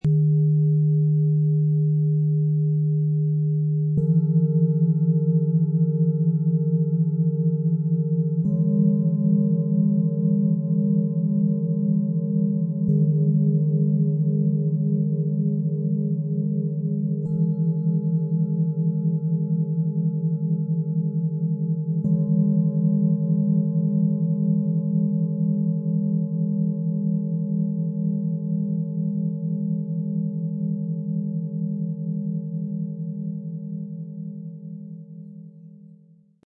Freundlich bewegt & gut zentriert - 3 Klangschalen für Kinder, Klangpädagogik & feine Klangarbeit - Set aus 3 Klangschalen, Ø 14,1 - 16 cm, 1,44 kg
Ihr Klang ist freundlich, bewegend und unterstützend - aktivierend, ohne zu überfordern.
Ihr Ton ist bewegend und leicht aktivierend, mit einer gut spürbaren Vibration, die sich angenehm überträgt.
Ihr Ton ist freundlich, hell und harmonisch.
Die Schale mit dem höchsten Ton besitzt einen besonders harmonischen und freundlichen Klangcharakter.
So entstehen lebendige Unikate mit einem warmen, ehrlichen Klang - genau richtig für die Arbeit mit Kindern und sensiblen Menschen.
Mit unserem Sound-Player - Jetzt reinhören können Sie den Original-Ton genau dieser drei Klangschalen anhören. Das Set klingt freundlich, bewegend und harmonisch - aktivierend, ohne zu überfordern, und zugleich zentrierend.
Bengalen-Schale, glänzend